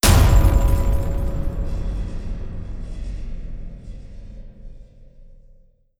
Jumpscare_08.wav